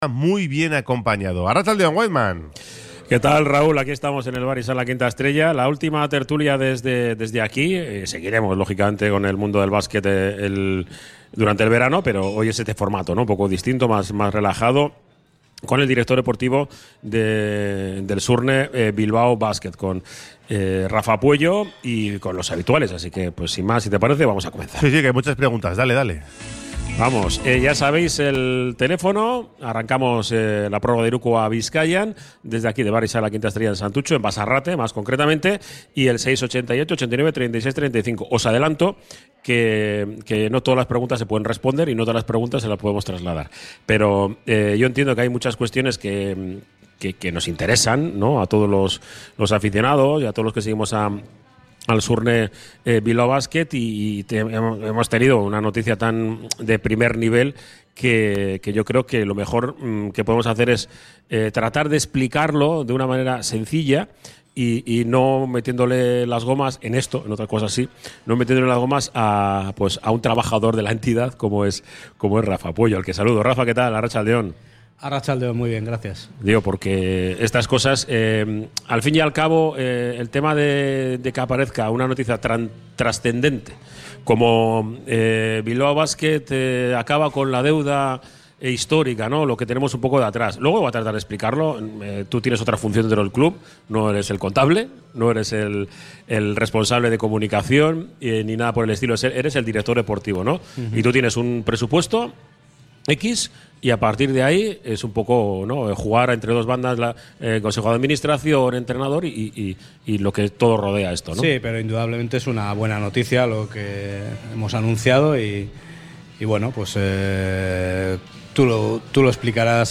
Desde el Bar Izar la Quinta Estrella